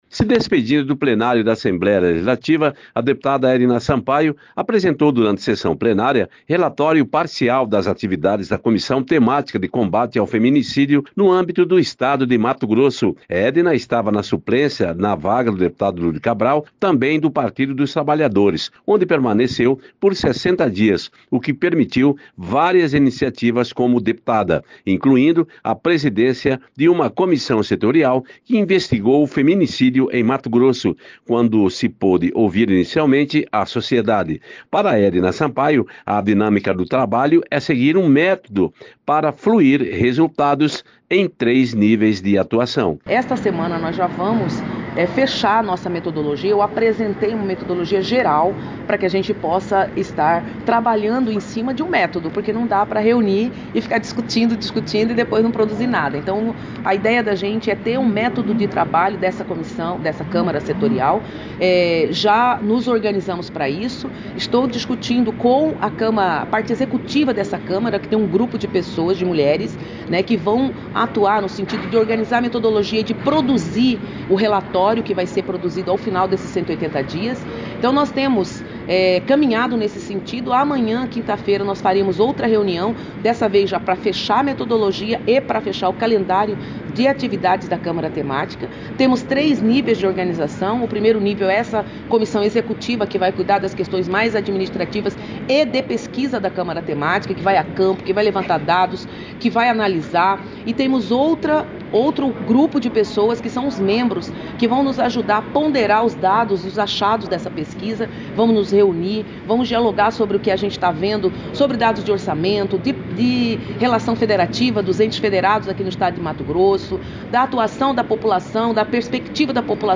Se despedindo do plenário da ALMT, enquanto suplente, a deputada Edna Sampaio apresenta o primeiro relatório da Câmara Setorial de Combate ao Feminicídio. Edna afirma que missão foi cumprida como deputada.